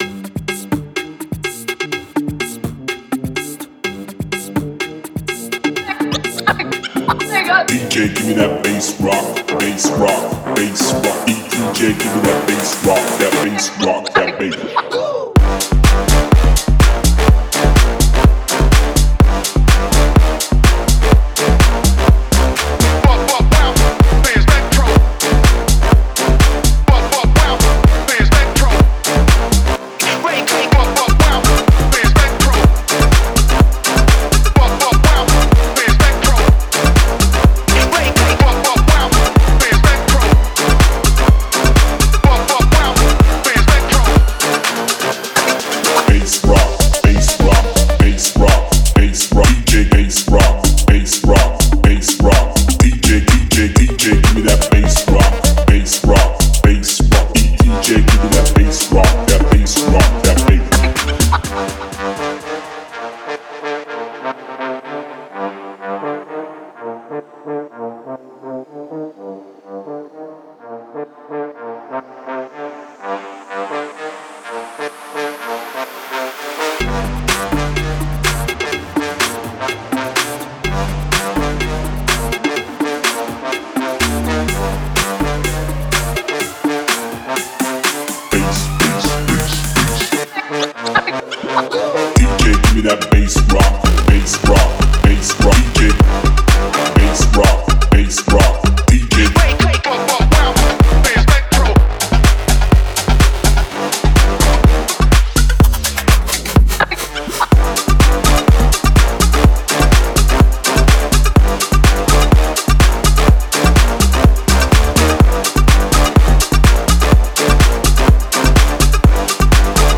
это динамичная композиция в жанре электронной музыки